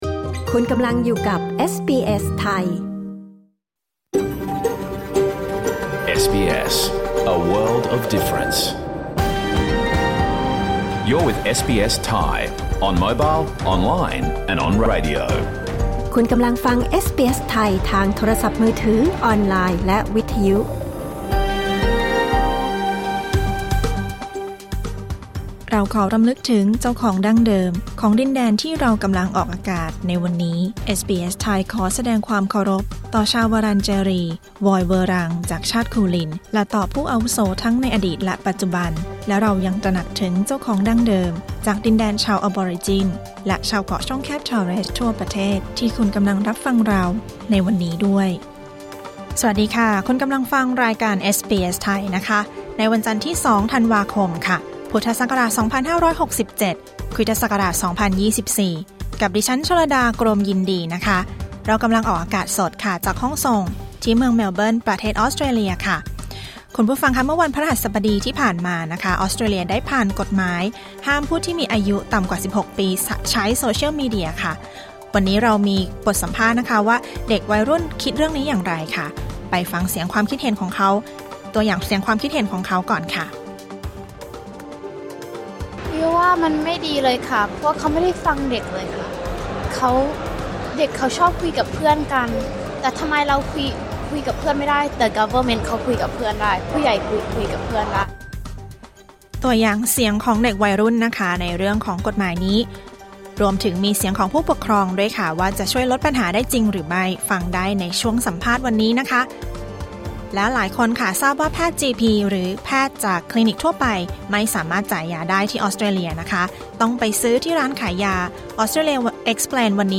รายการสด 2 ธันวาคม 2567